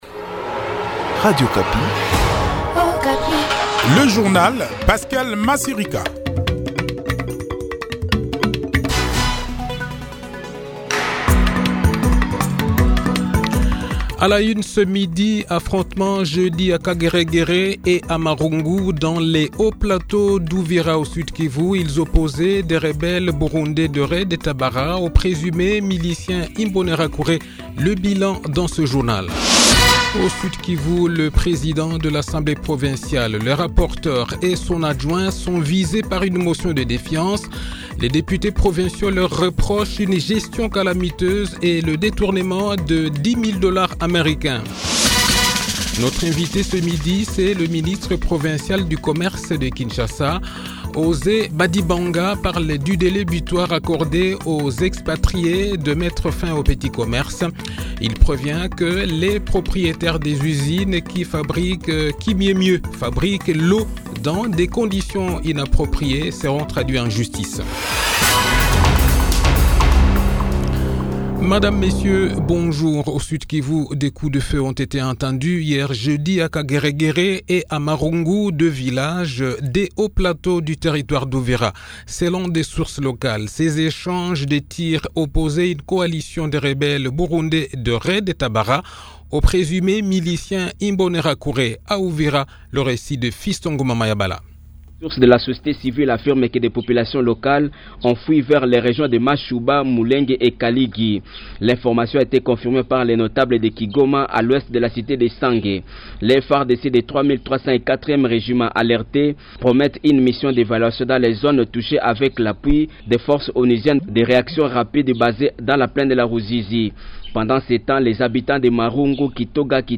Le journal de 12 h, 2 Avril 2021